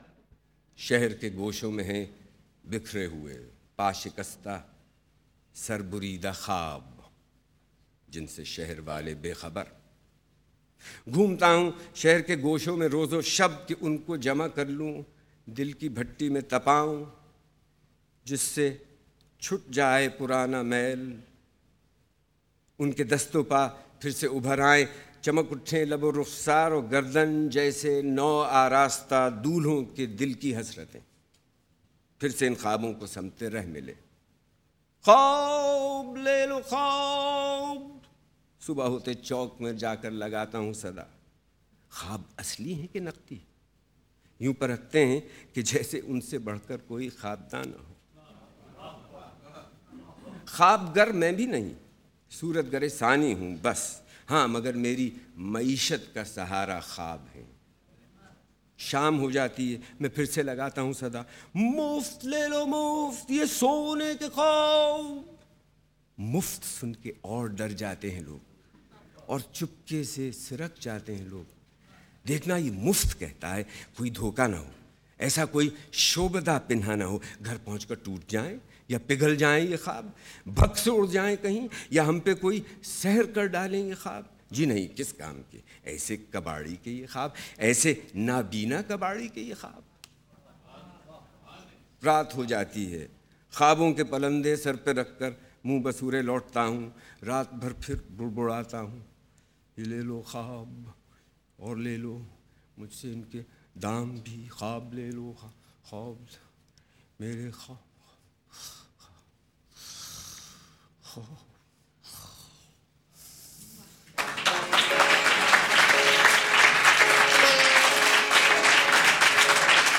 Zia Mohyeddin reading of Urdu Adab (Prose and Poem) is considered legendary, the way he delivers each work, with his pauses and due emphasis. Below is recitation of Zarguzasht, Mushtaq Ahmed Yusufi’s evergreen masterpiece.